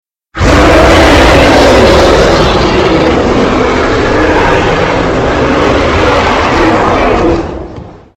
Громовой рев ярости